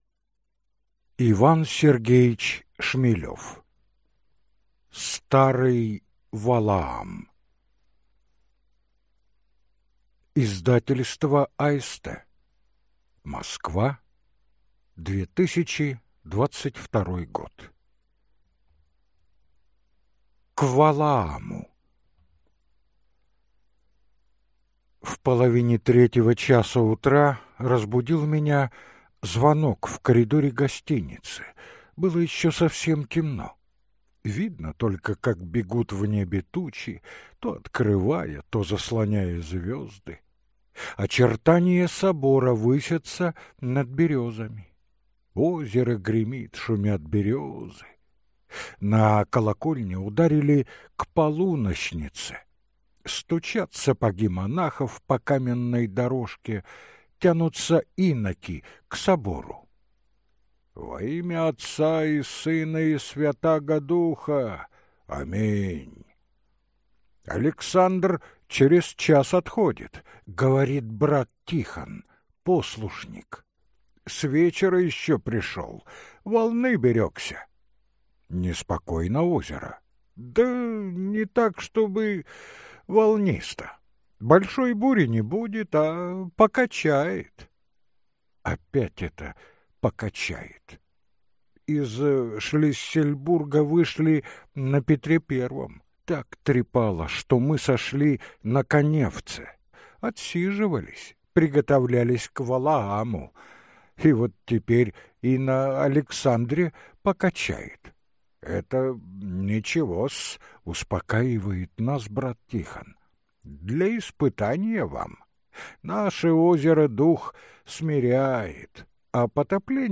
Аудиокнига Старый Валаам | Библиотека аудиокниг